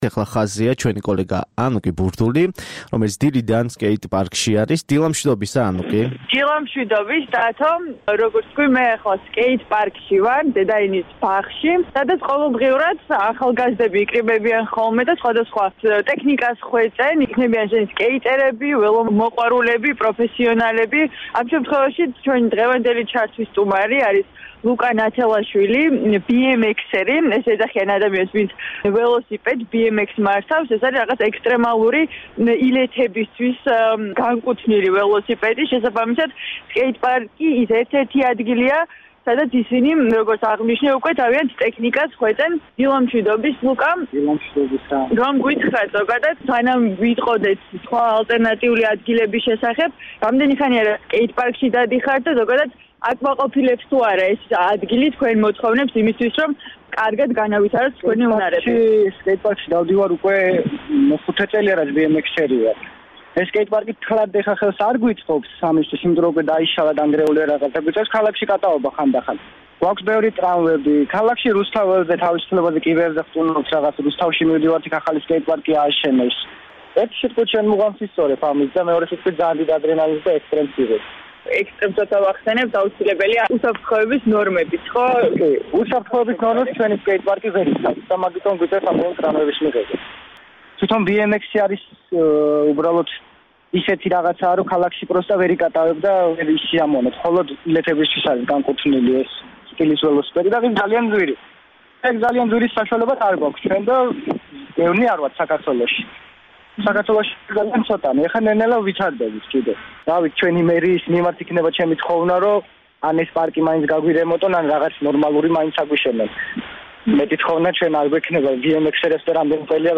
რომლითაც სკეიტპარკსა და ქალაქის სხვადასხვა ადგილს სტუმრობს. სატელეფონო ჩართვაში ის სკეიტპარკის განახლების საჭიროებისა და ამ ალტერნატიული ადგილების შესახებ საუბრობს.